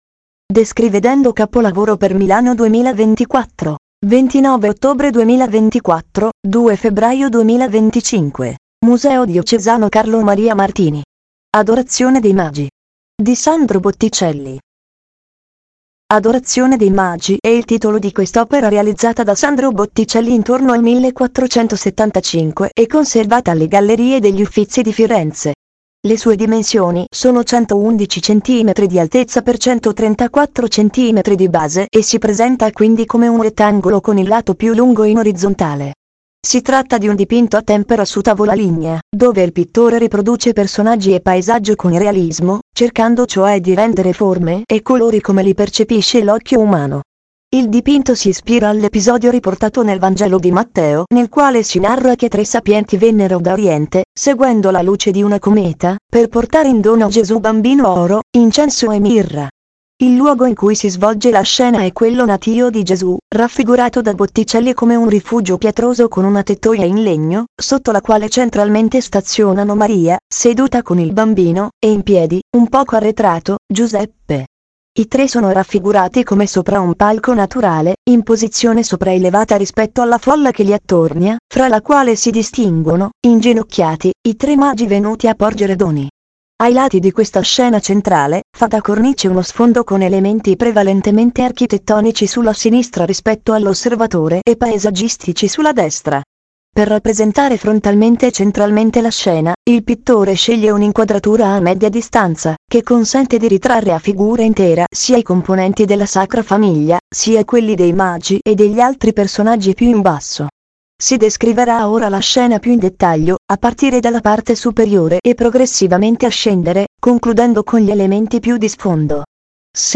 Adorazione dei magi Sandro Botticelli Leggi la descrizione dell’opera Guarda l’immagine ingrandita dell’opera Ascolta la descrizione dell’opera